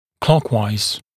[‘klɔkwaɪz][‘клокуайз]по часовой стрелке